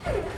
engine-crank.wav